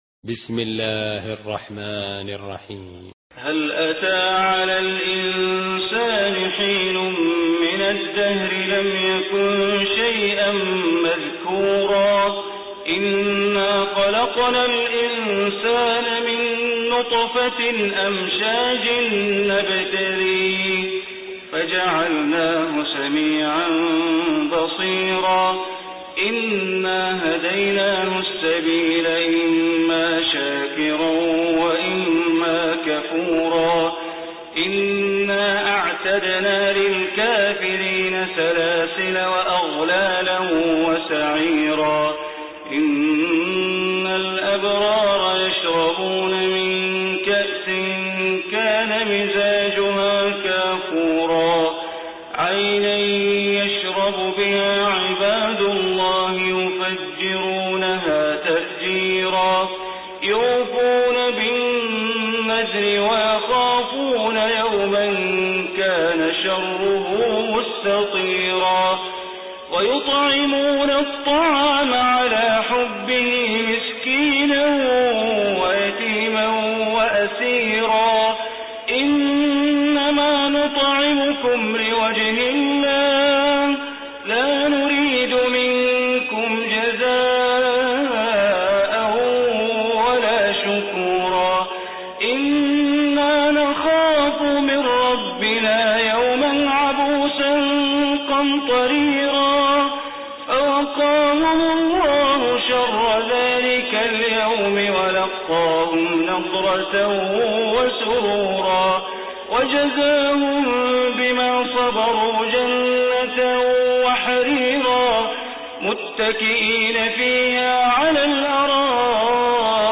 Surah Insan Recitation by Sheikh Bandar Baleela
Surah Insan, listen online mp3 tilawat / recitation in Arabic recited by Imam e Kaaba Sheikh Bandar Baleela.